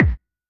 Index of /kb6/Roland_SH-32/Drumset 04
040 Plastic BD 2.wav